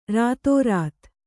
♪ rātōrāt